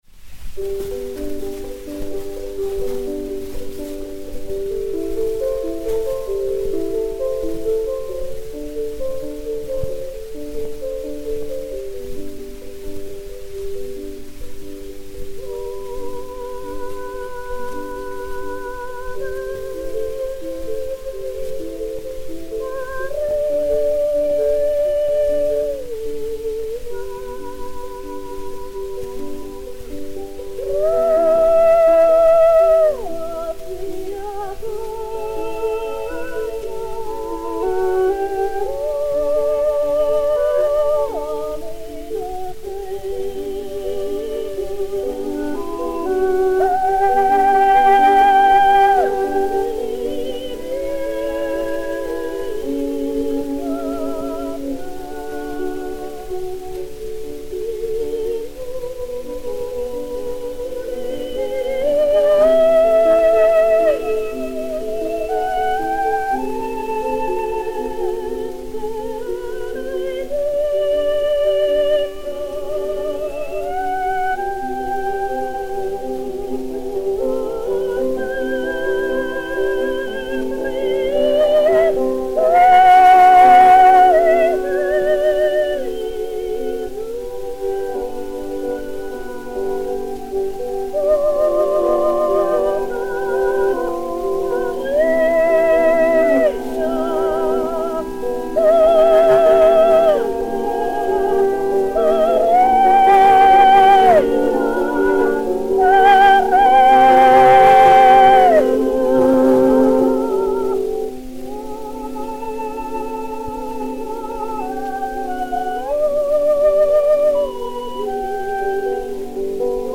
Mélodie religieuse, paroles liturgiques, musique adaptée au 1er Prélude de J.-S. Bach par Charles GOUNOD (1853).
Nellie Melba et Jan Kubelik (violon)
Disque Pour Gramophone 03033, mat. 401c, enr. à Londres en octobre 1904